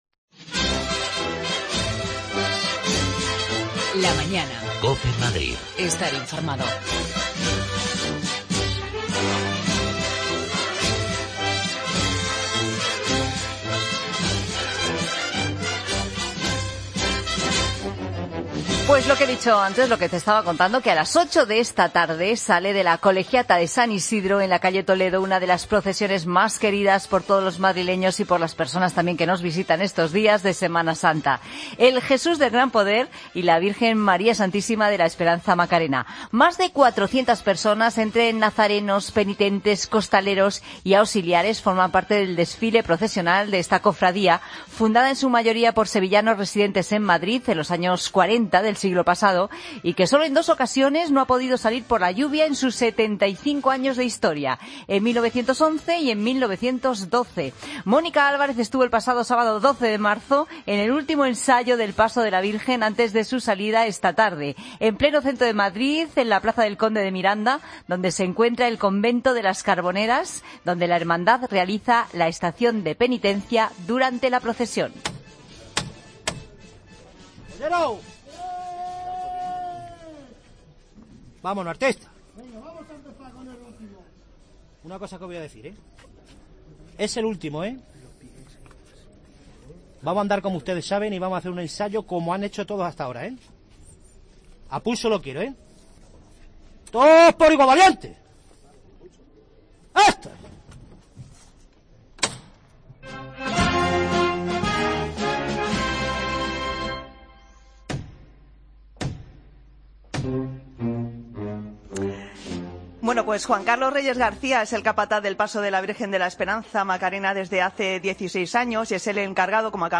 Nos colamos en el último ensayo.